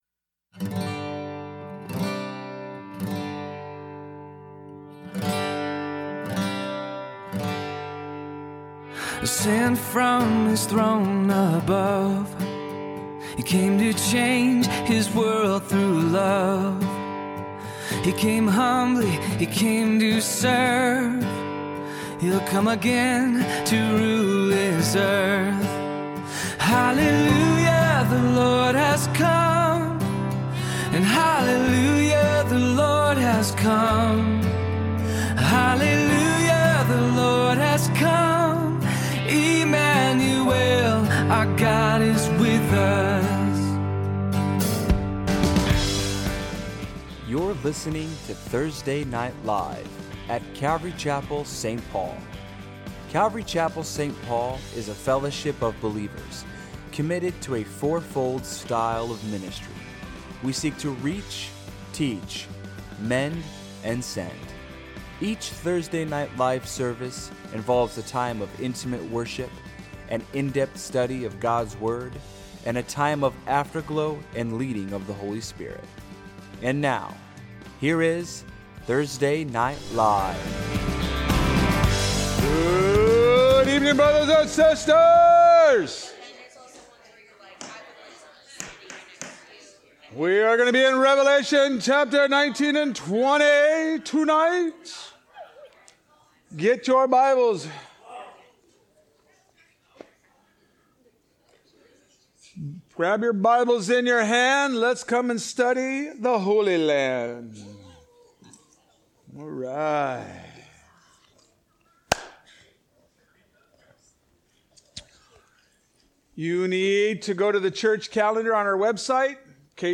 A message from the series "3000 Series."